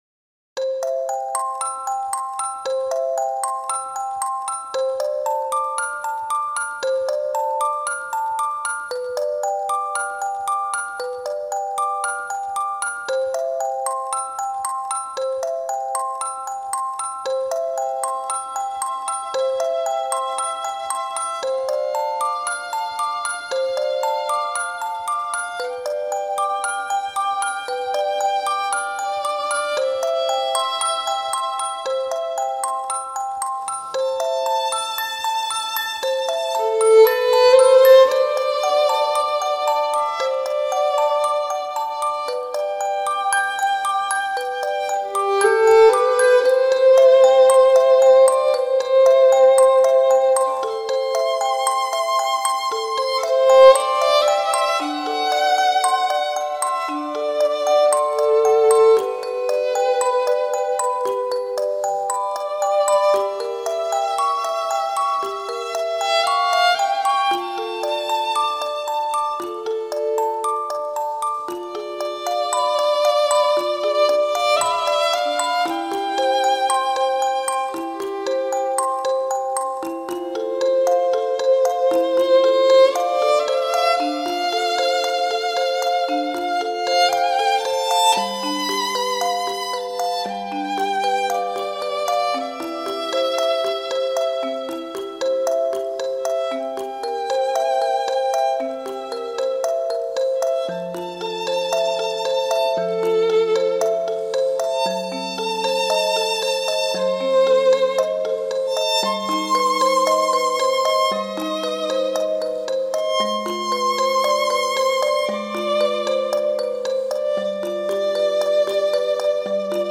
Аве Мария - колыбельная - слушать онлайн
Аве Мария - нежная колыбельная, классика. Нежная и расслабляющая мелодия классического произведения для детей.